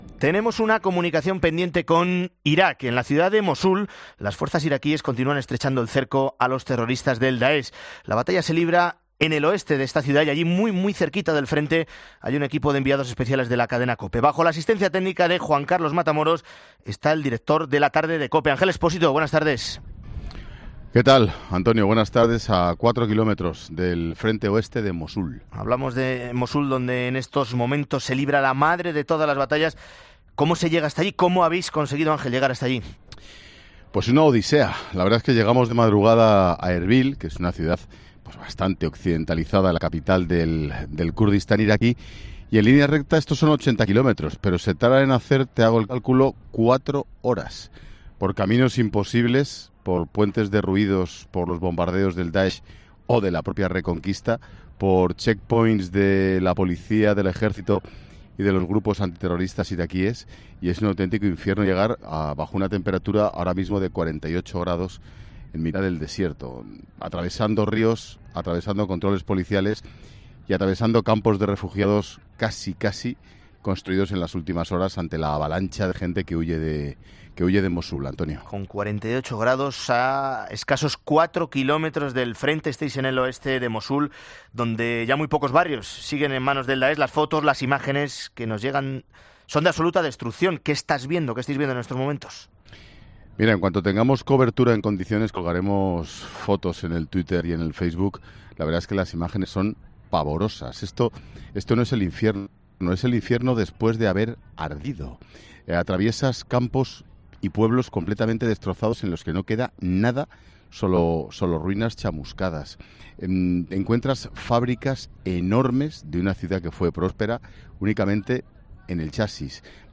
Monólogo de Expósito
Ángel Expósito, a 4 kilómetros del frente oeste de Mosul